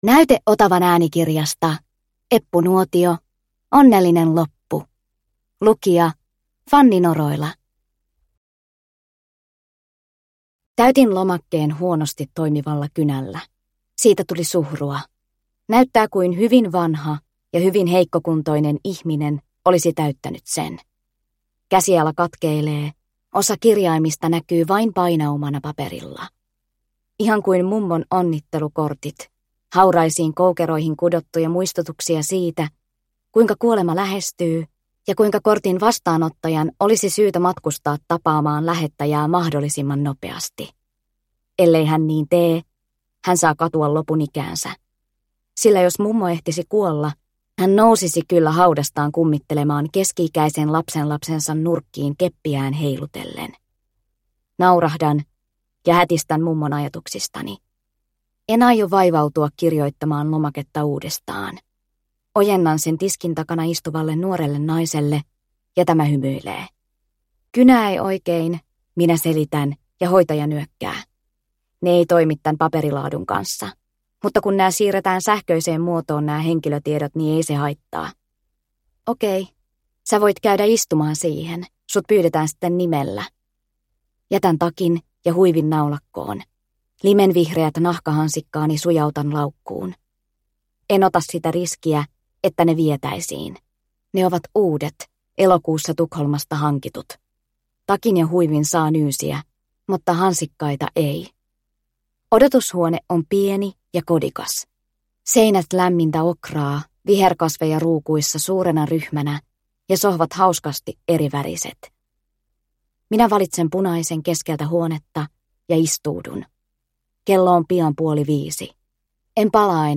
Onnellinen loppu – Ljudbok – Laddas ner